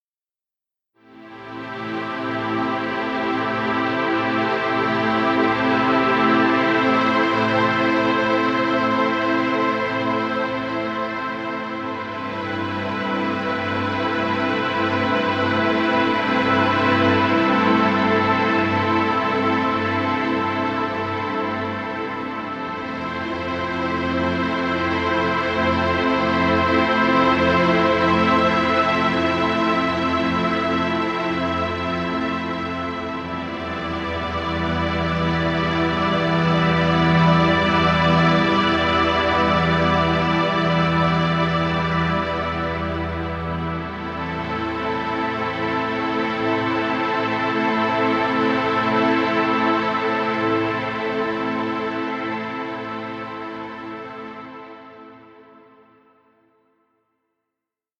Relaxing music.